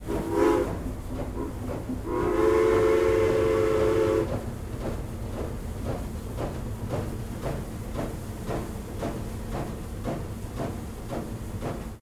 Airborne Sound - Steam Train